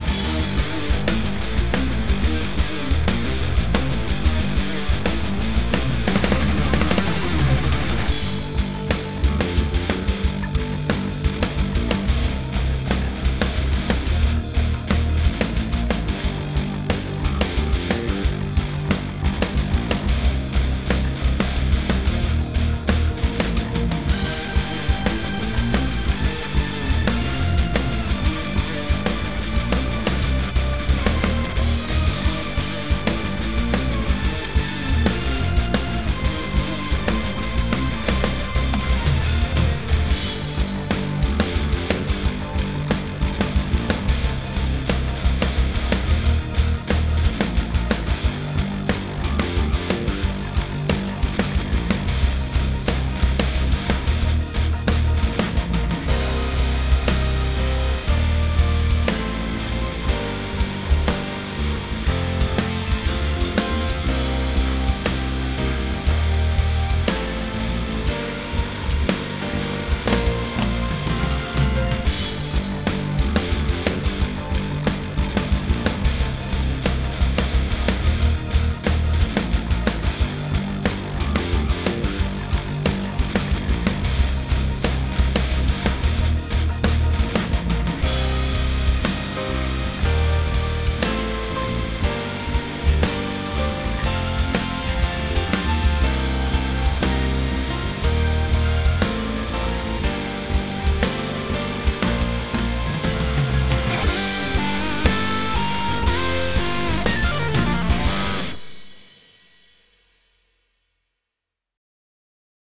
Konkursowe podkłady muzyczne:
PODKŁAD 2,